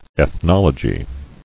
[eth·nol·o·gy]